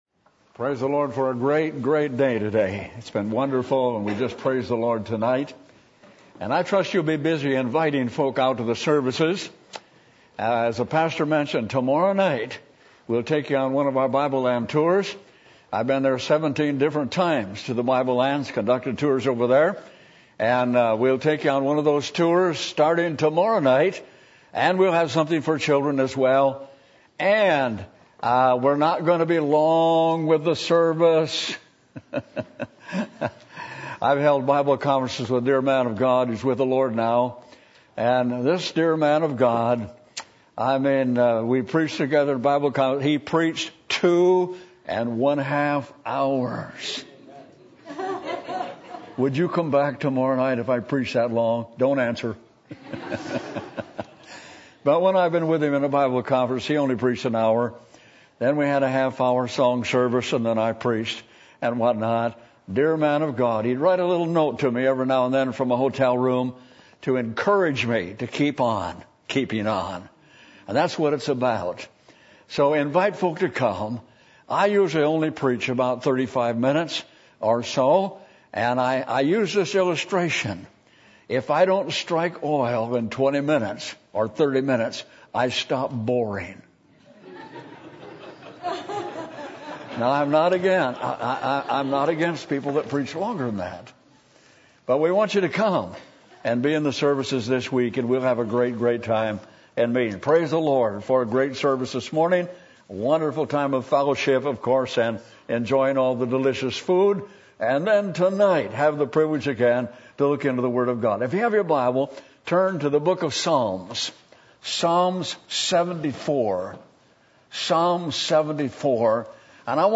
Psalm 74:10-12 Service Type: Sunday Evening %todo_render% « What Does It Cost To Become A Real Christian?